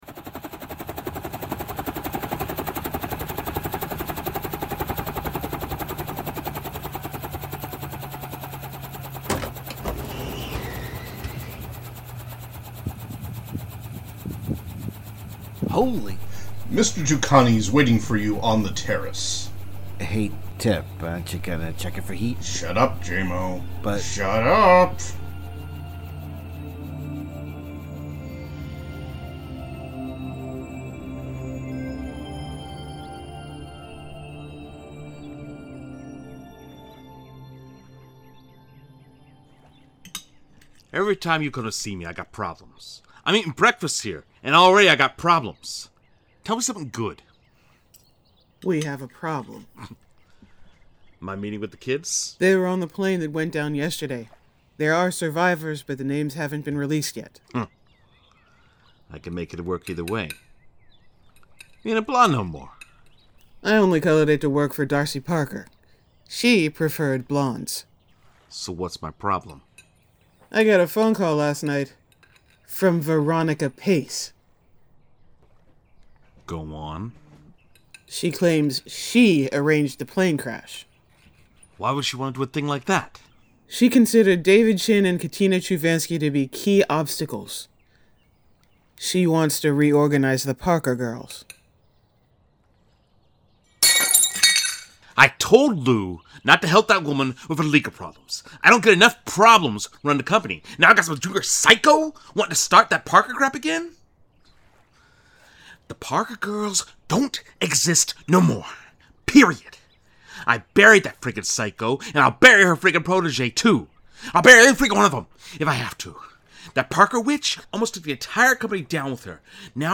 Strangers In Paradise – The Audio Drama – Book 8 – My Other Life – Episode 5 – Two True Freaks